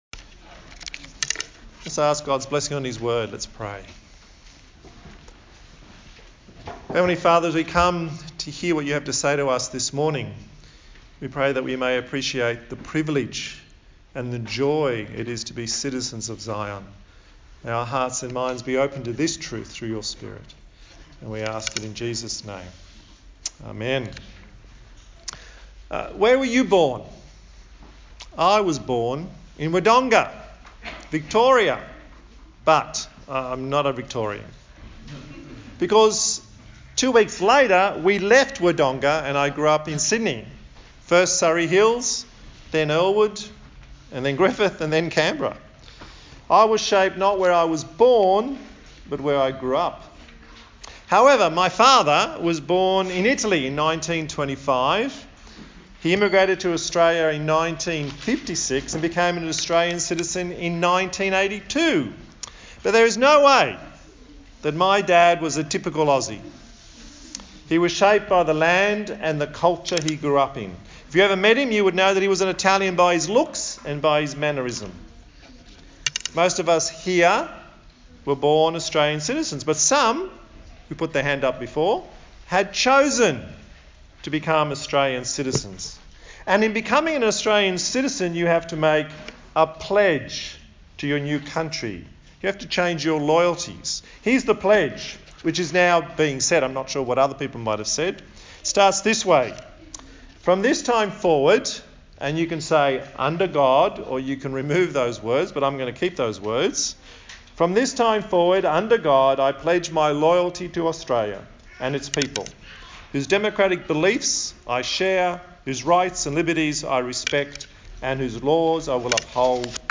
A sermon in the series on the book of Psalms
Service Type: Sunday Morning